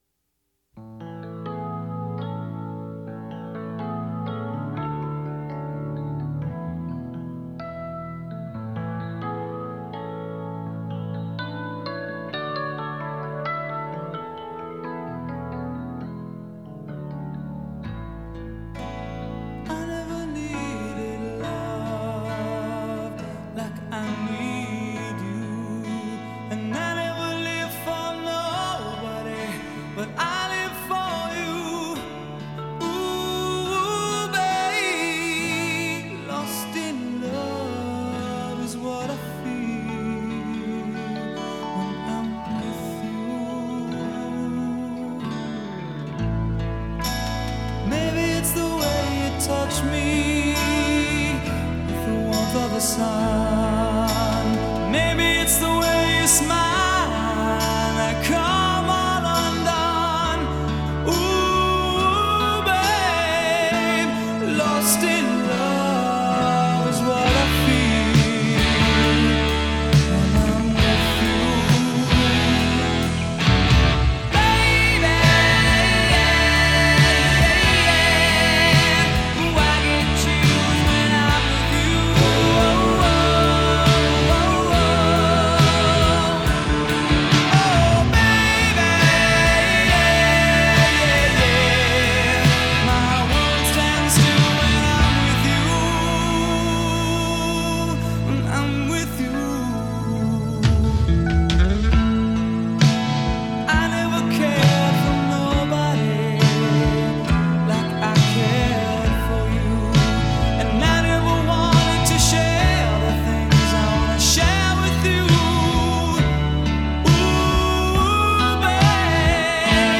ballad
all of those helium voiced power ballads